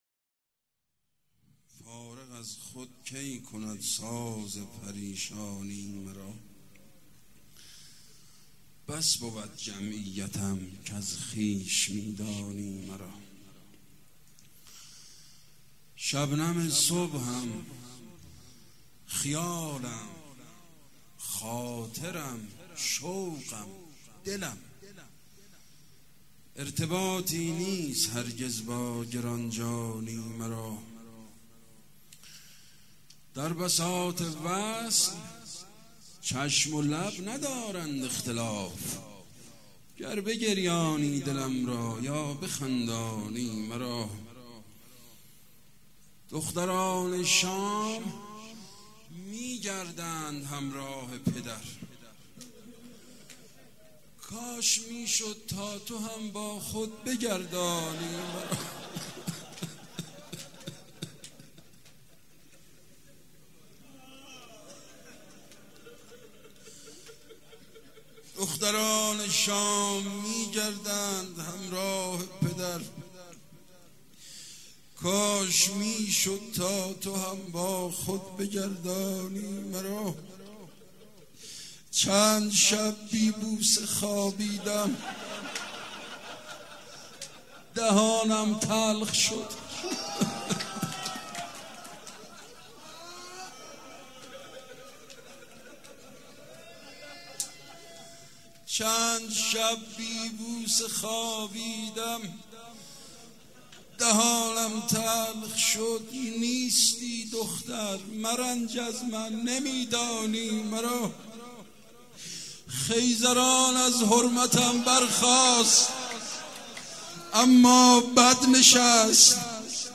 شب هفتم رمضان95